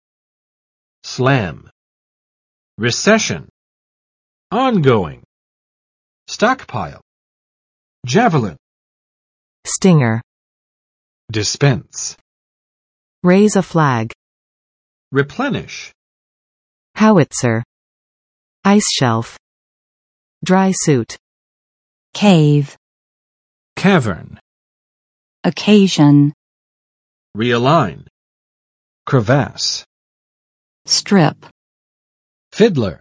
[slæm] v. 猛击